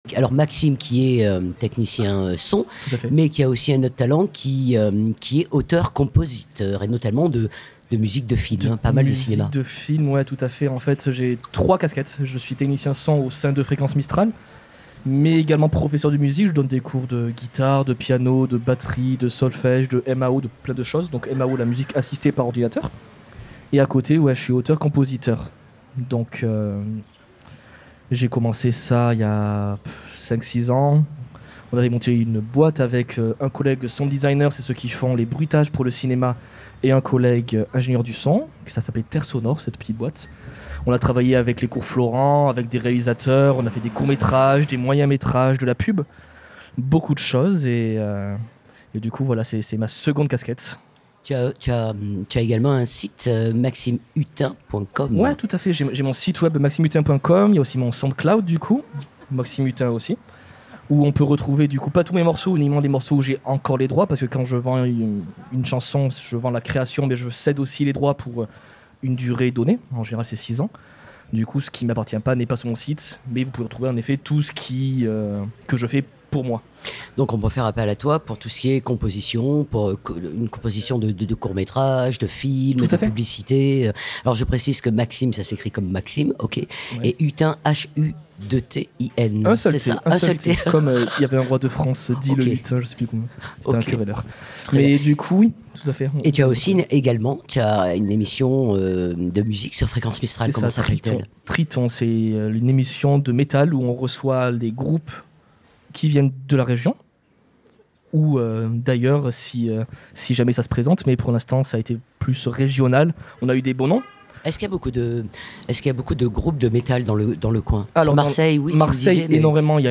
Le 5 et 6 juin 2025 c'était la fête de la Radio dans toute la France. A cette occasion, toute l'équipe de Fréquence Mistral s'est retrouvée afin de vous proposer un plateau délocalisé en direct sur toute la journée sur Manosque.